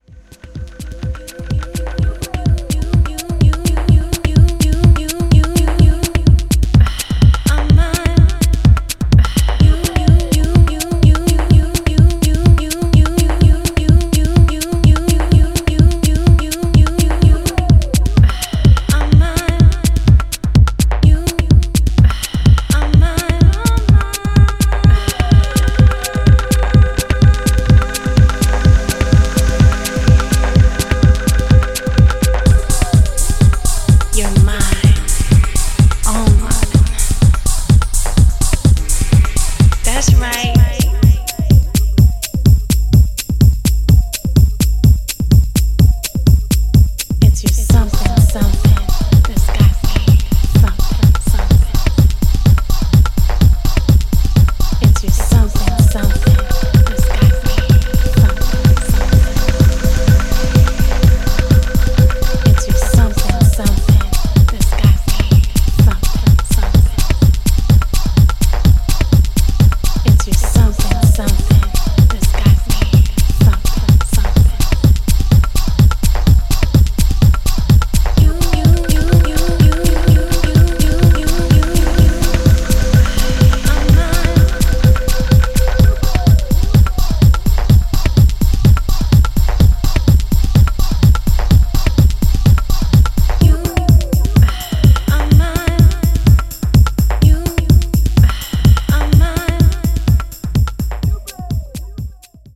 has produced three percussive and ready-to-use tracks!